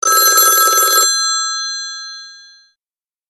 без слов
старый телефон
трель